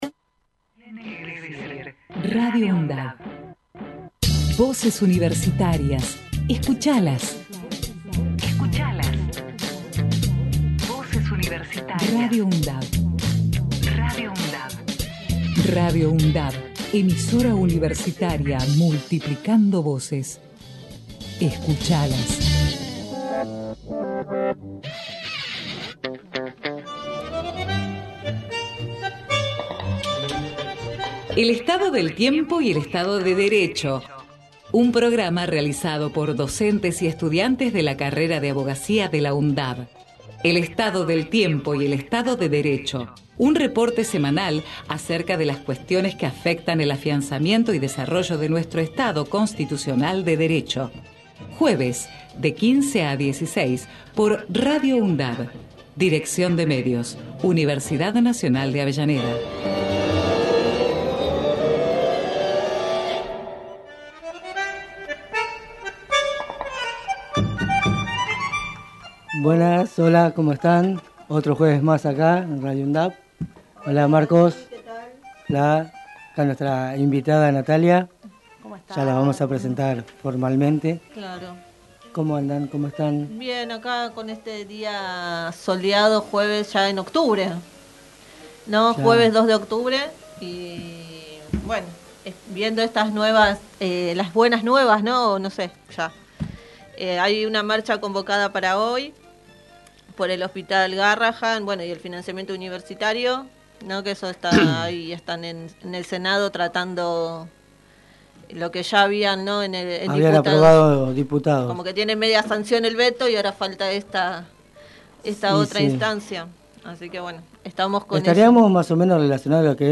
El Estado del Tiempo y el Estado de Derecho Texto de la nota: El Estado del Tiempo y el Estado de Derecho es un programa realizado por estudiantes y docentes de la carrera de Abogacía de la Universidad Nacional de Avellaneda, fue emitido por Radio UNDAV desde el año 2016 todos los jueves de 15 a 16hs.